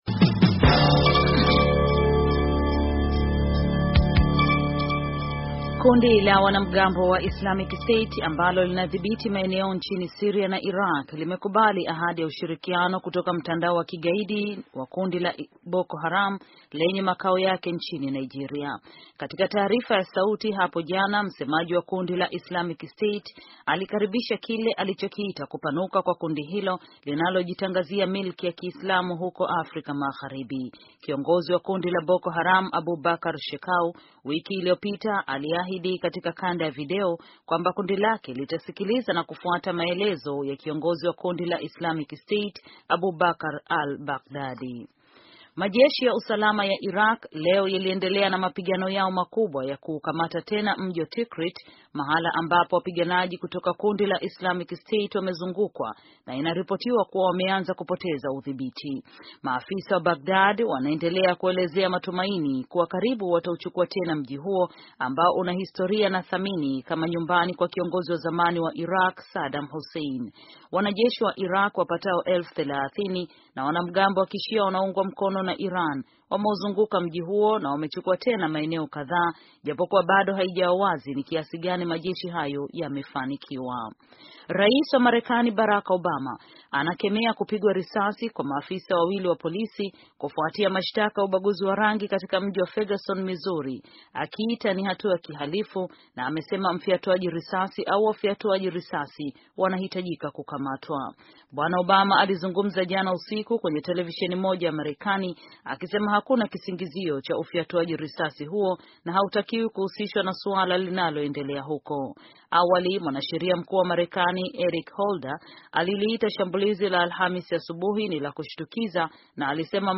Taarifa ya habari - 4:25